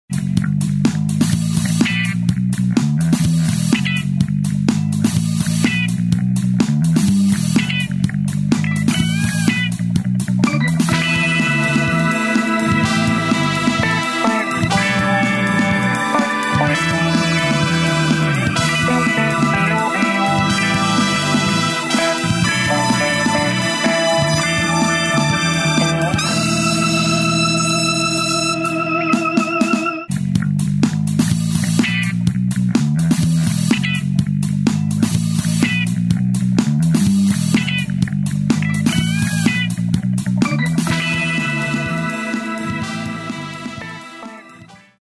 Politono de Piqueto